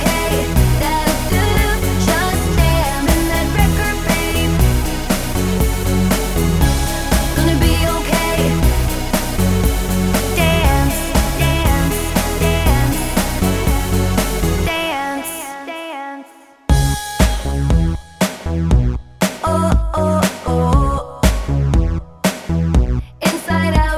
For Duet Pop (2010s) 4:01 Buy £1.50